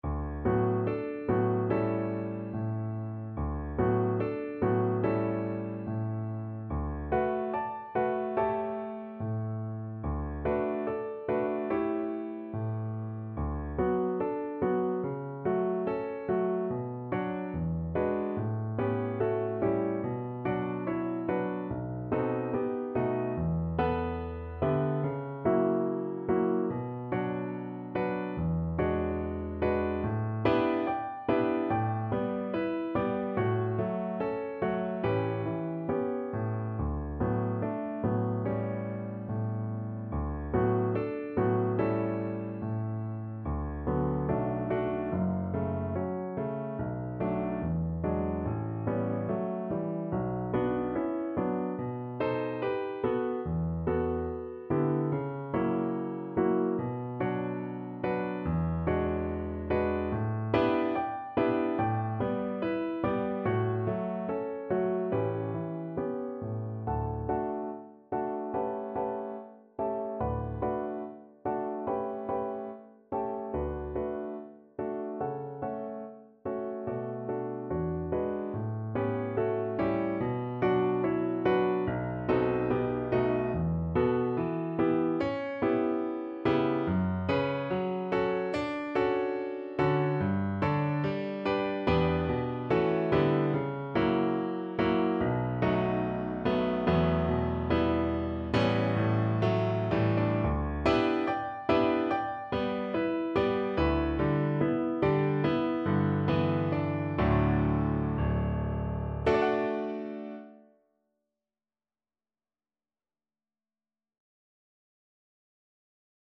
~ = 72 In moderate time
Classical (View more Classical Voice Music)